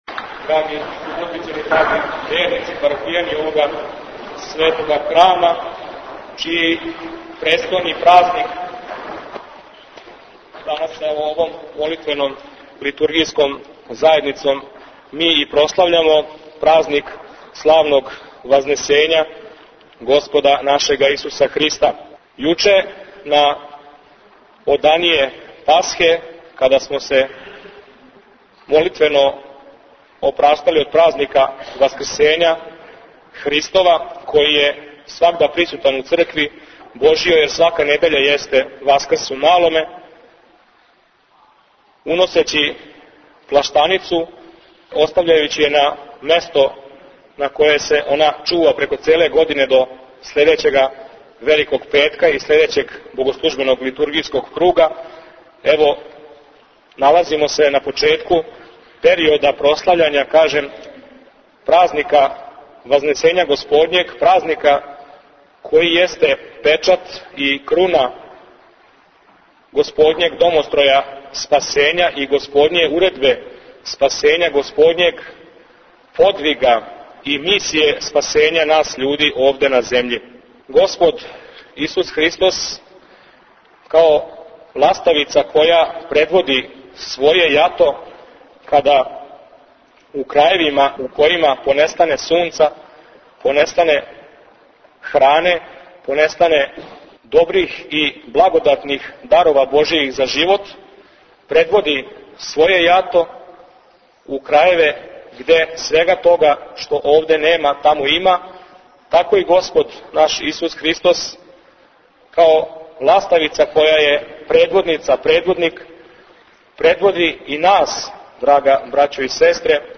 који је одржао пригодну беседу.